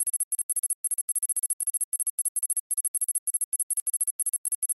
Электронный звук взлома пароля